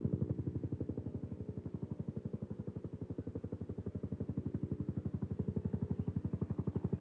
This helicopter sample has two missing pieces.  The montage approach is used to identify and replace these pieces.  Notice that the replaced events preserve the quasi-periodic nature of the sample.
Helicopter original Damaged
HelicoptermontSynRecovered.wav